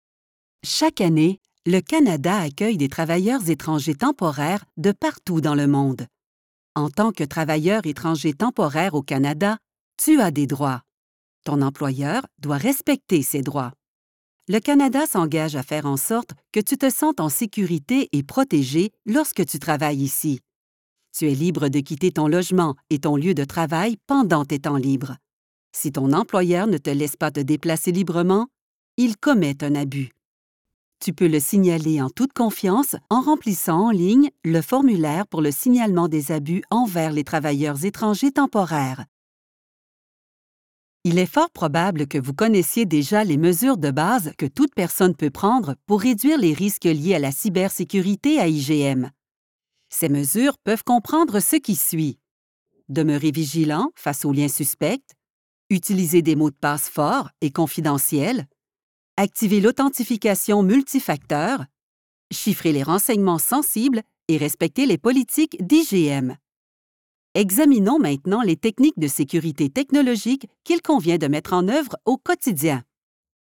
Accessible, Reliable, Corporate
E-learning